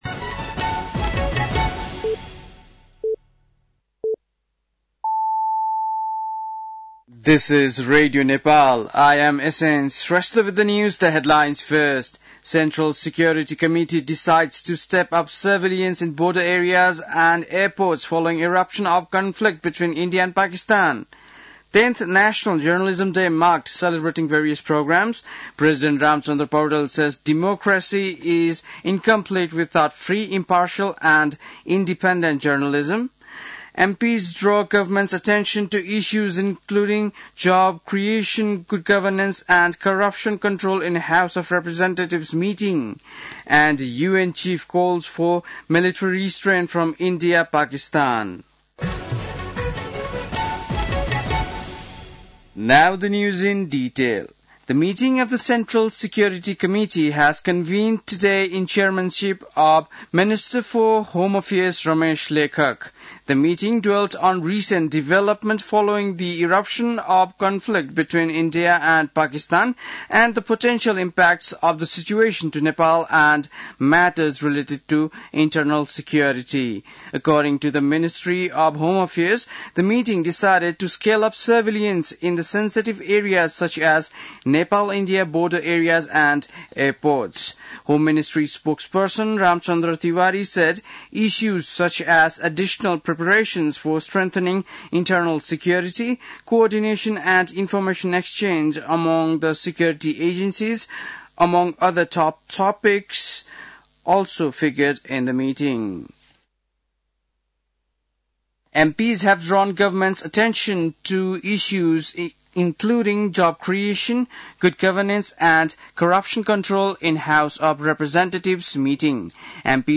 बेलुकी ८ बजेको अङ्ग्रेजी समाचार : २४ वैशाख , २०८२
8-pm-english-news-1-24.mp3